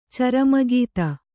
♪ carama gīta